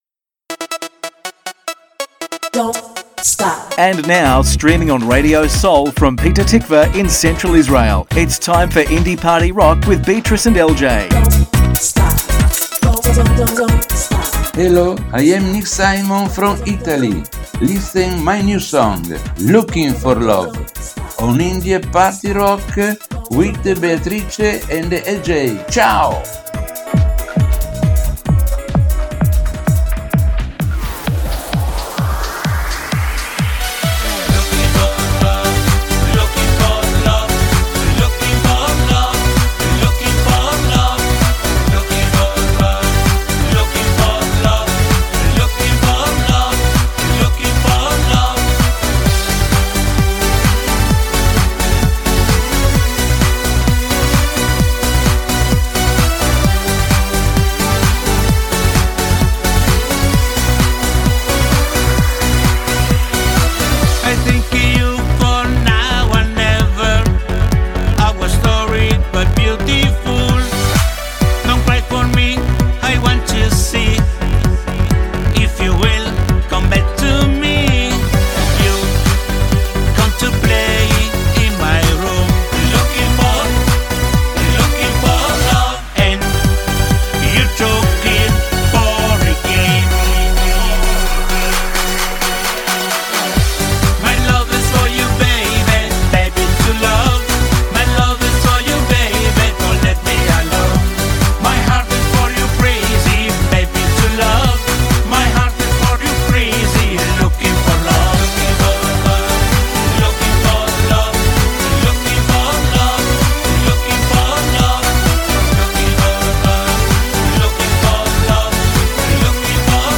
מוזיקת קאנטרי ואינדי עולמית - התכנית המלאה 29.11.24